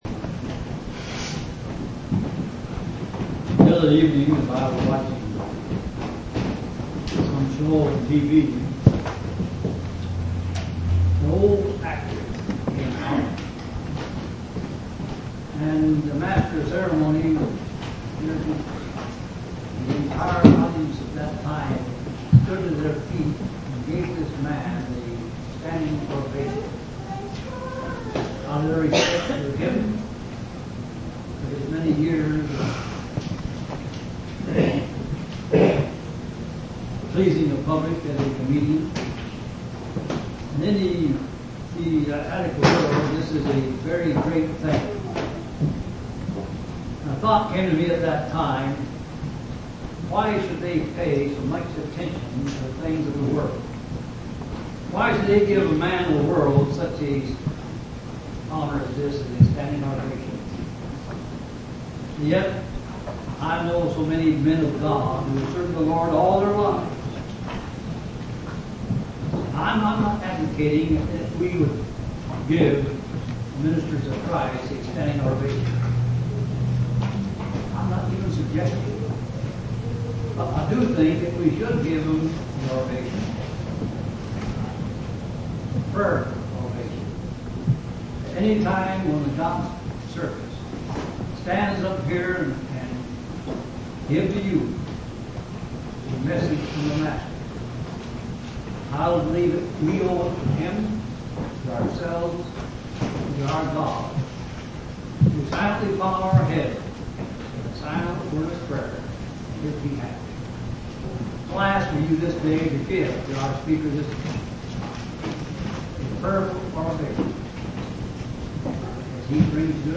8/15/1977 Location: Missouri Reunion Event: Missouri Reunion